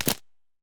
creaking_freeze1.ogg